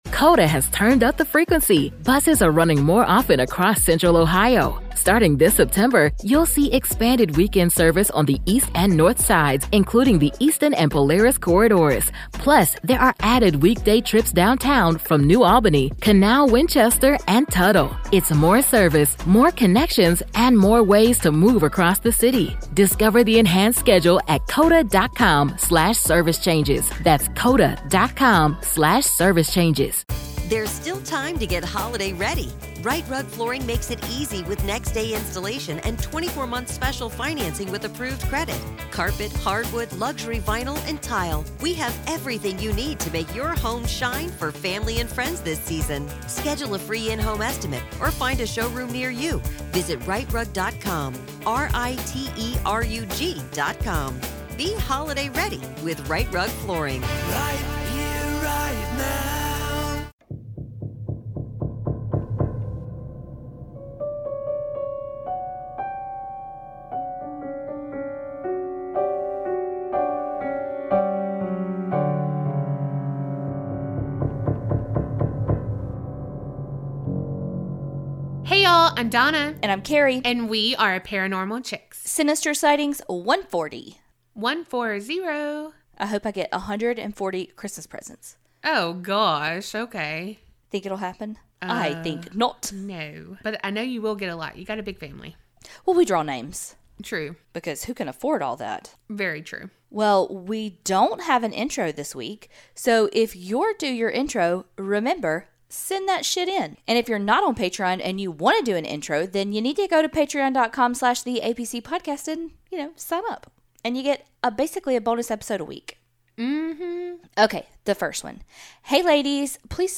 Sinister Sightings are your true crime and true paranormal stories. Every week we read out ones that you've sent in.